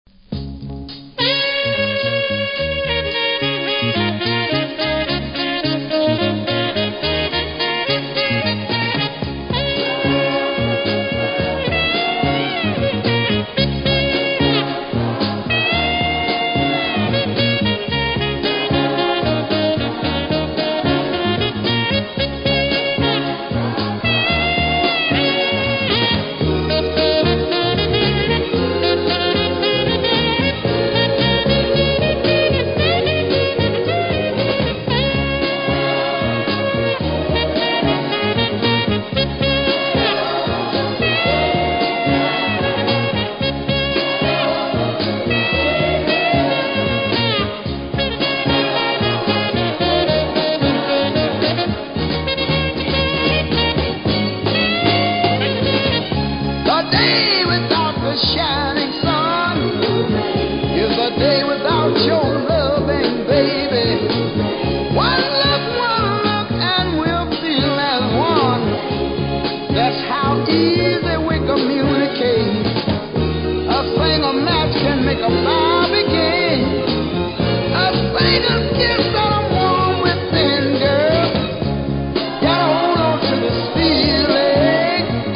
Soul
無録音部分に少しプレスノイズ感じますが音が出れば気にならないレベルなので問題無し。